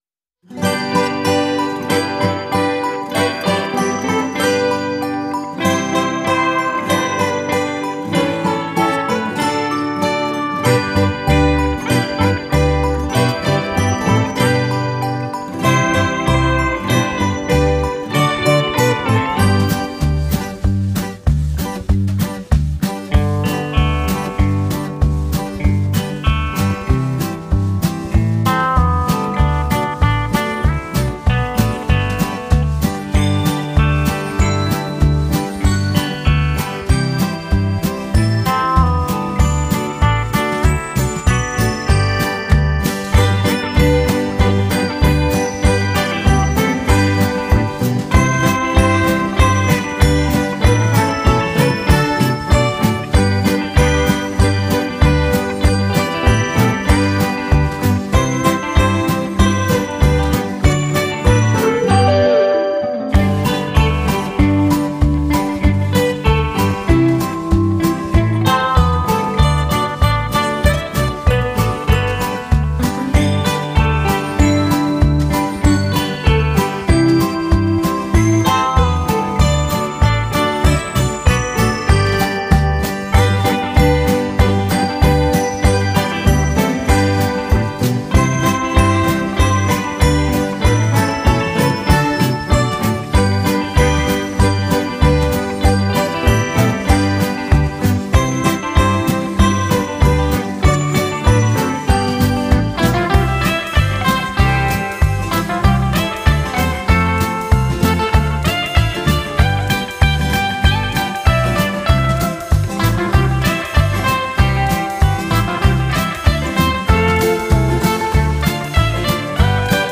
без слов праздничные